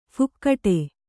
♪ phukkaṭe